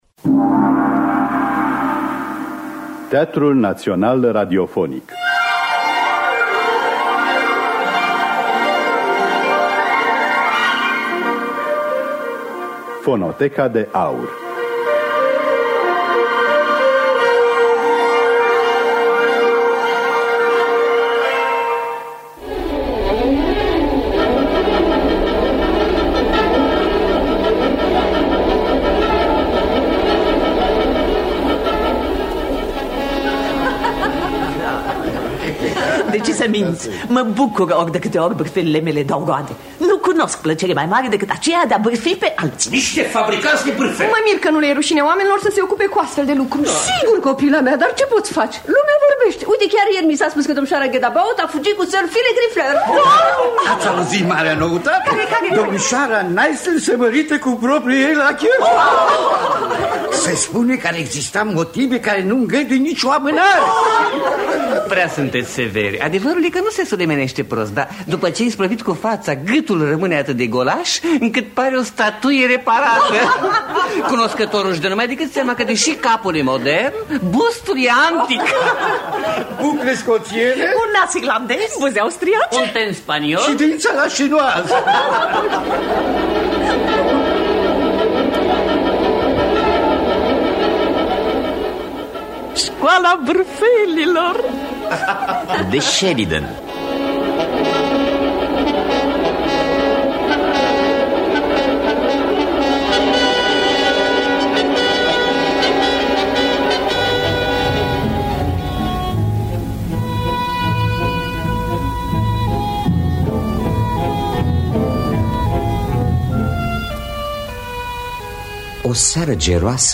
– Teatru Radiofonic Online
Adaptarea radiofonică
Înregistrare din anul 1957.